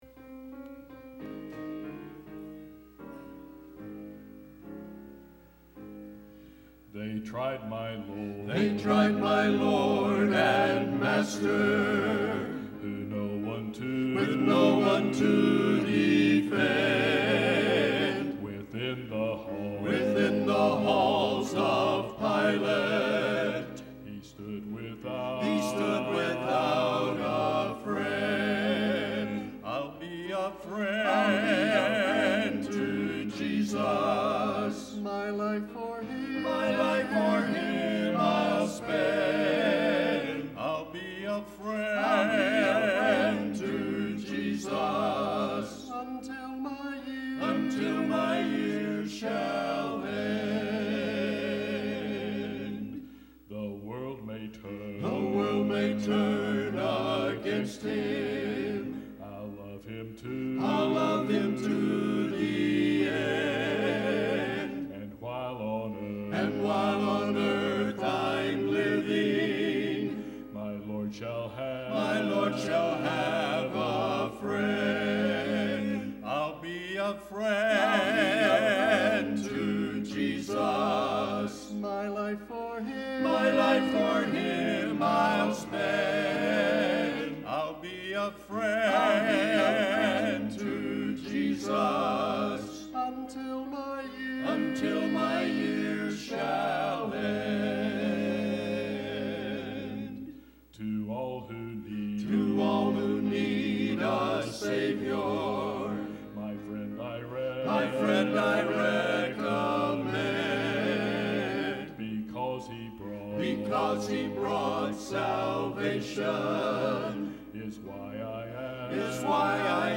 “I’ll Be A Friend To Jesus” – Faith Baptist Quartet
ill-be-a-friend-to-jesus-faith-baptist-quartet-2011.mp3